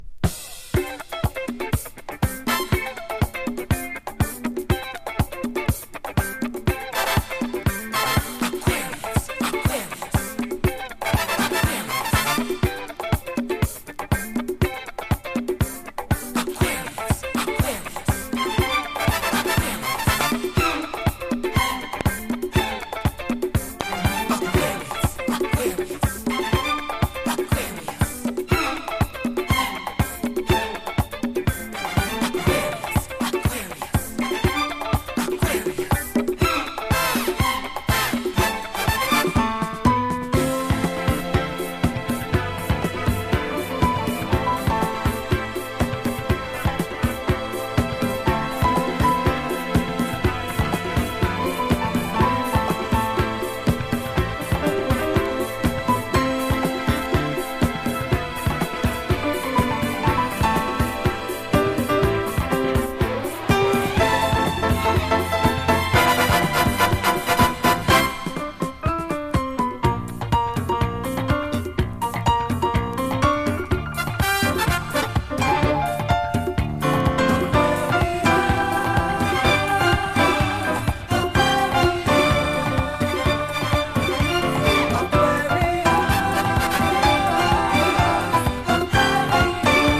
ラテンっぽい雰囲気にコーラス・パートが盛り上がるナイス・カヴァー!!中盤のカッティング・ギターがたまらないです...。